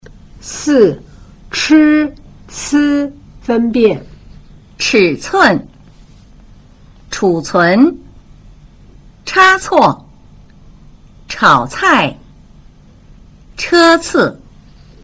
1. 平舌音 z c s和 捲舌音 zh ch sh 的比較﹕
4） ch – c分辨